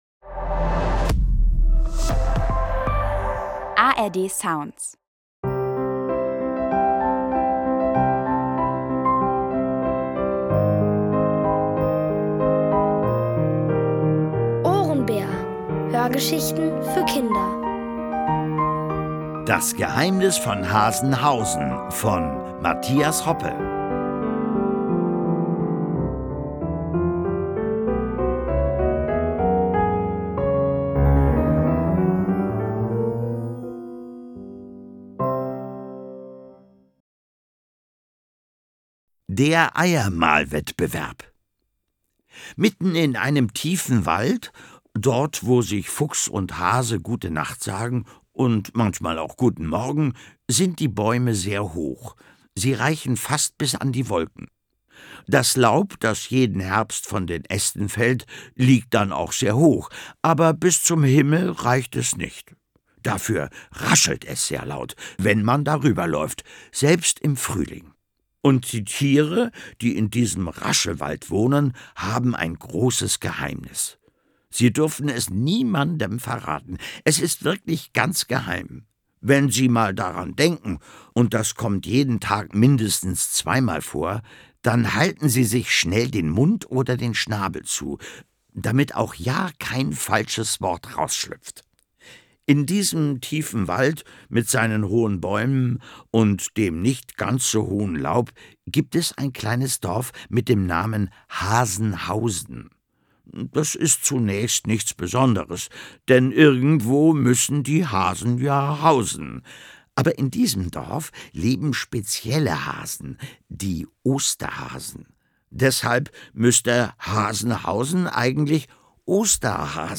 Es liest: Tommi Piper.